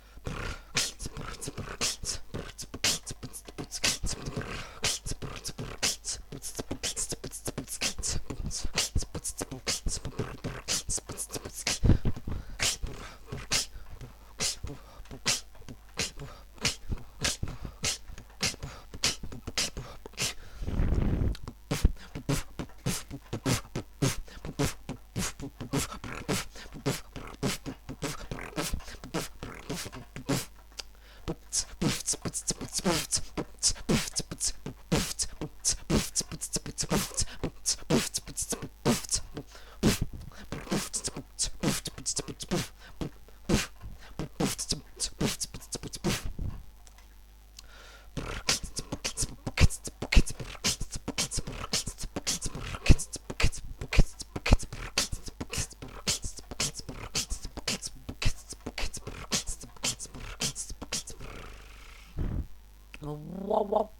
но сделай звуки менее человечными и более инструментальными ;]